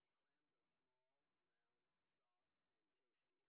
sp21_street_snr10.wav